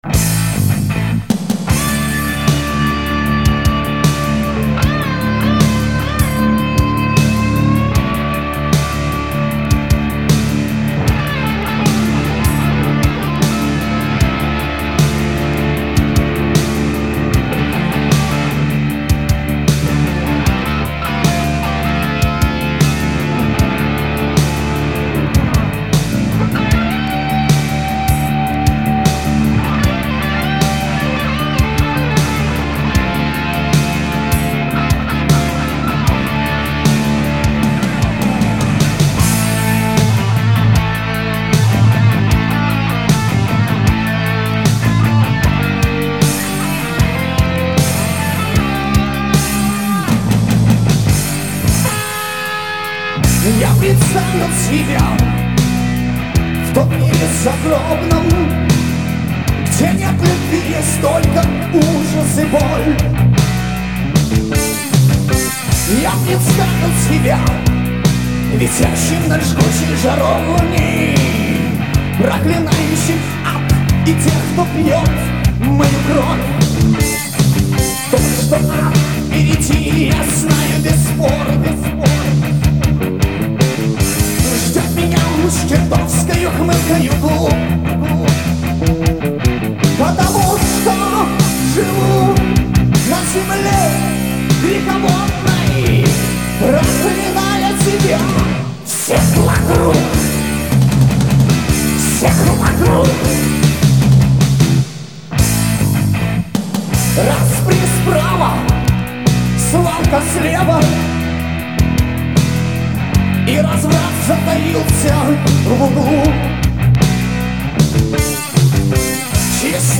вокал
гитара
бас
барабаны
Запись студии "Диалог" (г. Николаев), 1991